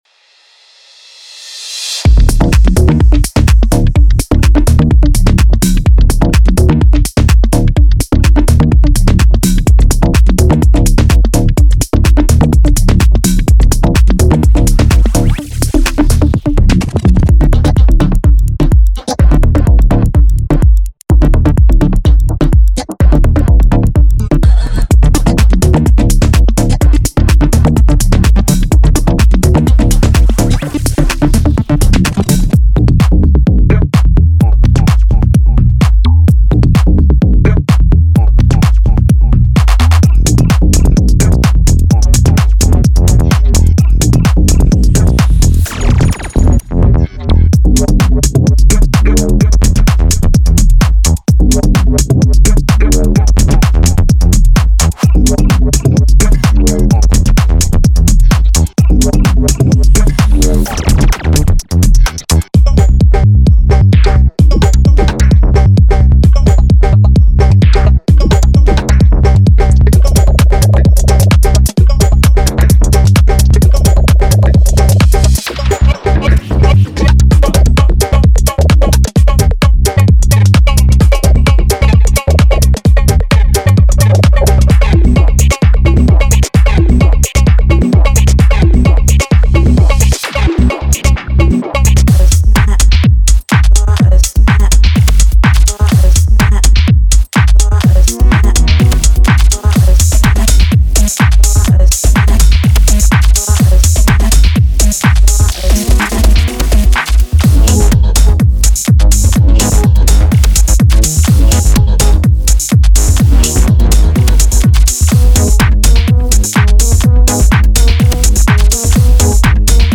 Genre:Tech House
BPM123から130までのテンポに対応し、現代のミニマルテックハウスの最適な範囲をカバーしています。
ご注意：オーディオデモはラウドに圧縮され、均一に聞こえるように処理されています。